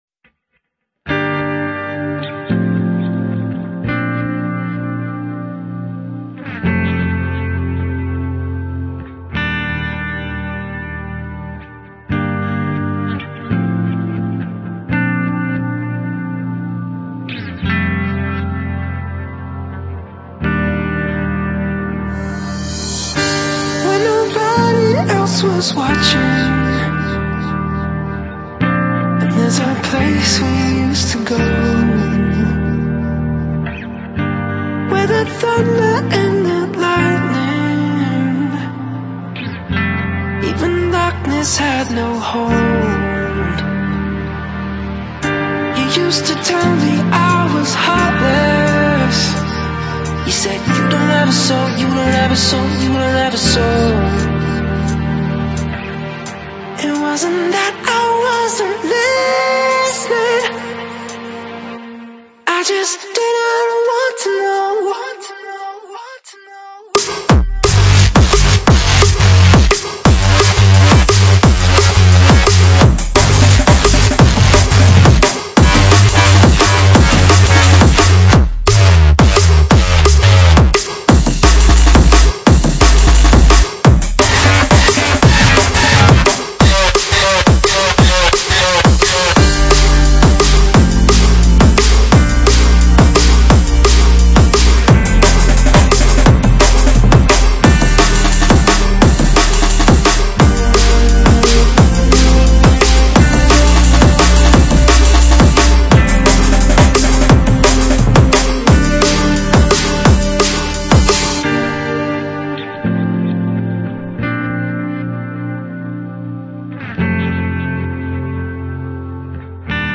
Стиль: Drum & Bass